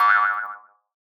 Cartoon Booing 10 Sound Effect Free Download
Cartoon Booing 10